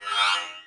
launchMenuOpen.ogg